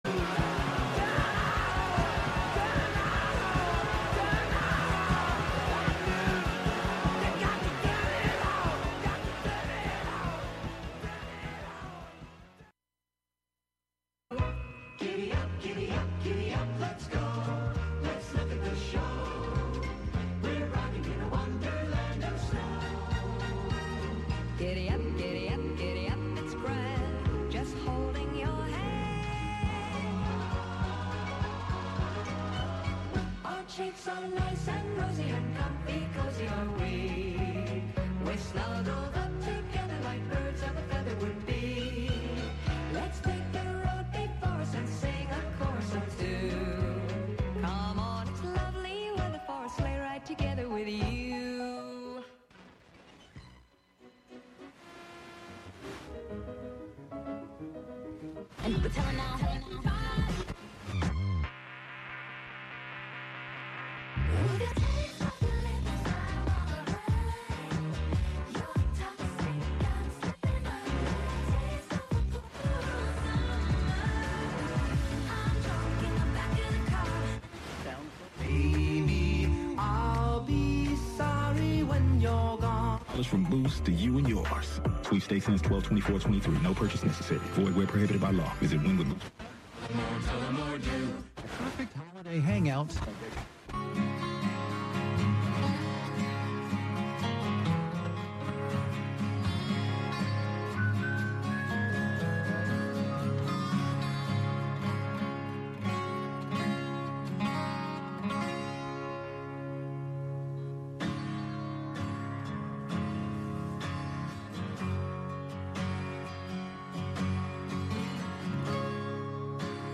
11am Live from Brooklyn, New York
turns the very bits and bytes of commercial culture into the driving backbeat to our dance of independence. "Radio Wonderland" abstracts live FM radio with laptop, electrified shoes hit with sticks, and a computer-hacked steering wheel (from a Buick 6).